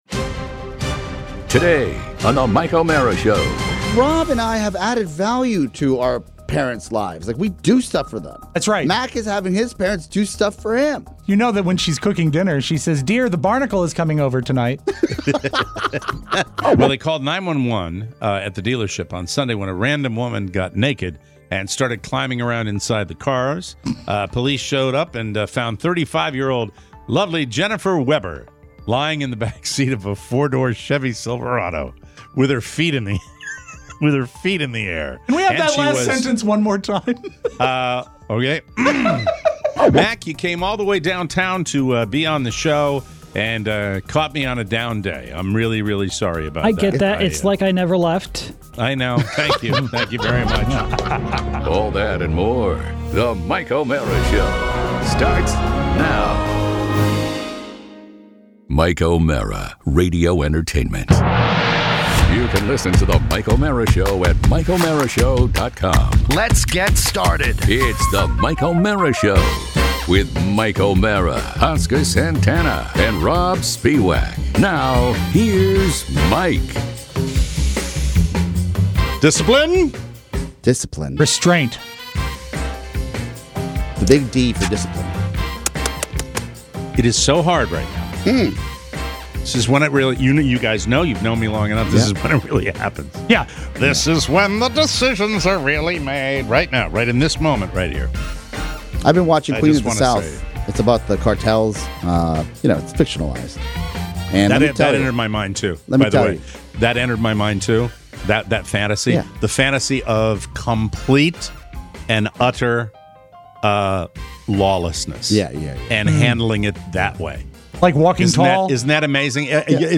We have a super special visitor in the studio today!